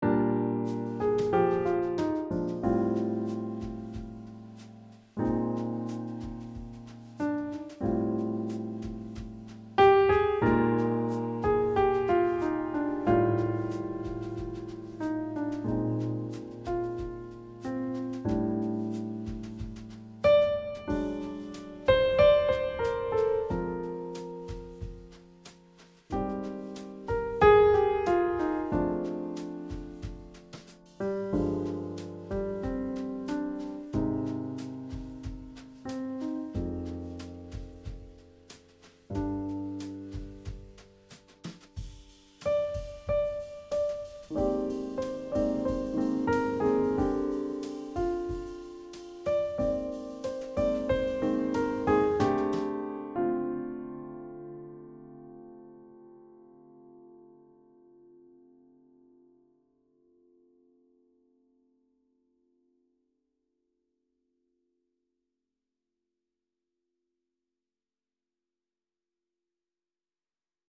Easy Jazz-Piano for Beginners and Advanced Players
Jazz/Improvisierte Musik
Sololiteratur
Klavier (1)